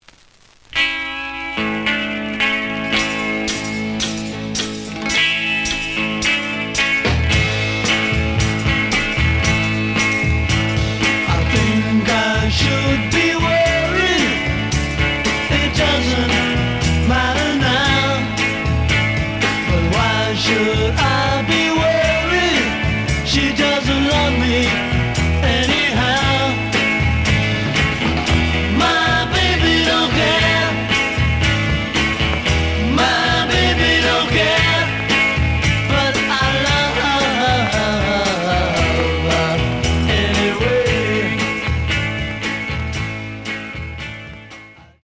The audio sample is recorded from the actual item.
●Genre: Rock / Pop
Some click noise on B side due to scratches.